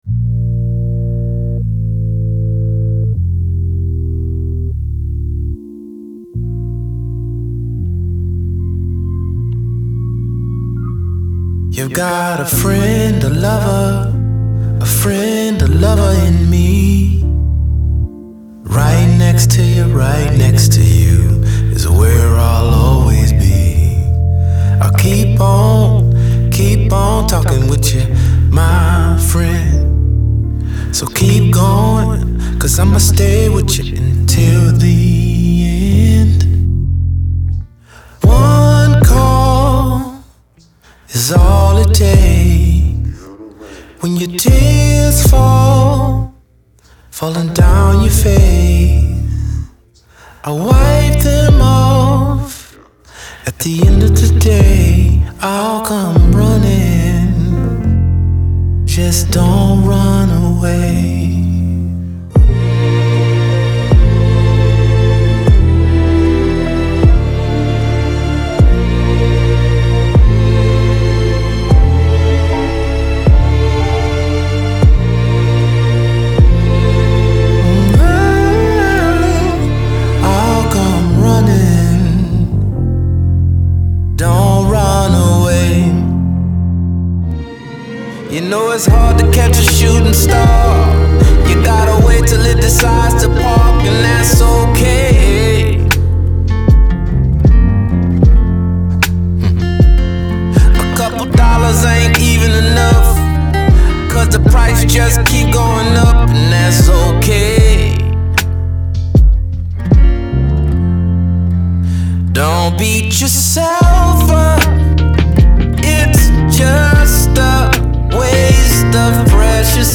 Genre : Pop